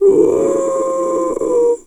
seal_walrus_2_death_03.wav